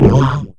animal anime boing creature cute funny happy noise sound effect free sound royalty free Funny